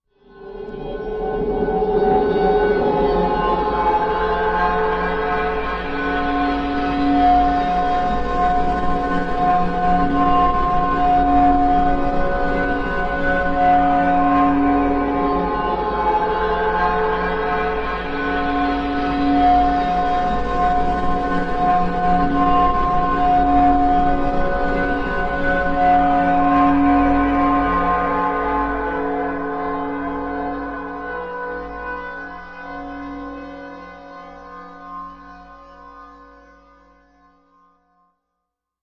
Steel Vortex Metallic Dissonance Distorting Wind Sweep